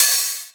010_Lo-Fi Big Open Hat_1.L.wav